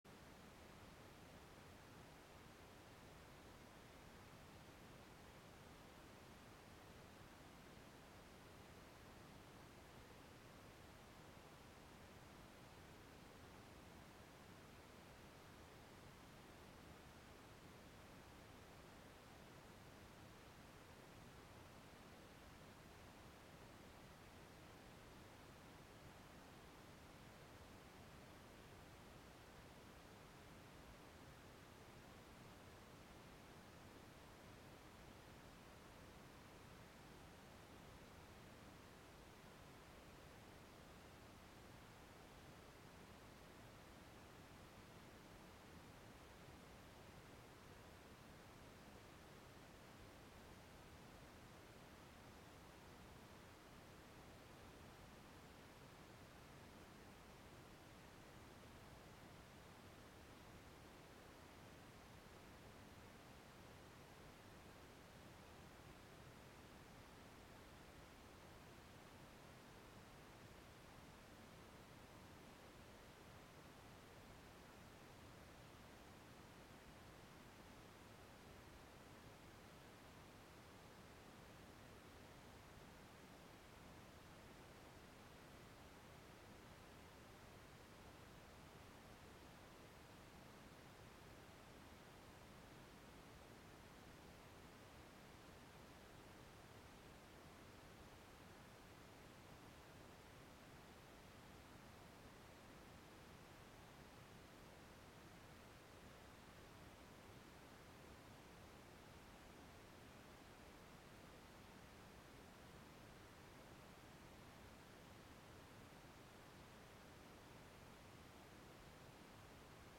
Broadcasting live from Catskill, NY.